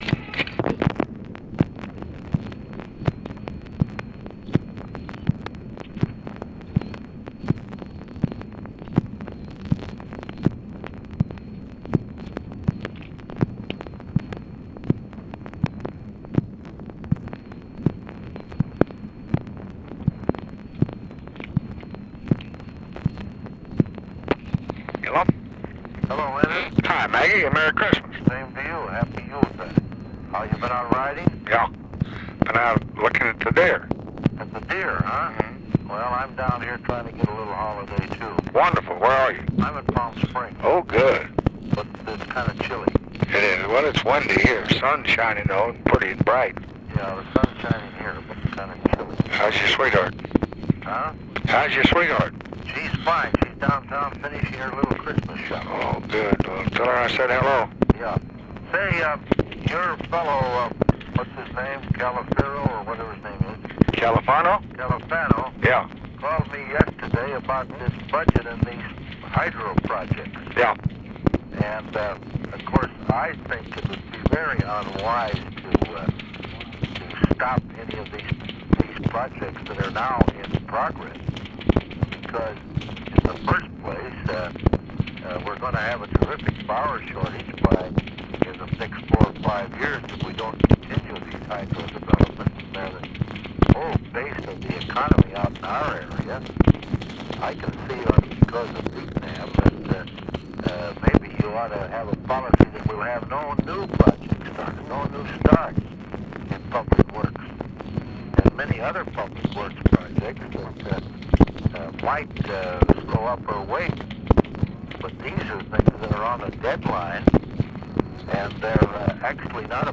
Conversation with WARREN MAGNUSON, December 24, 1965
Secret White House Tapes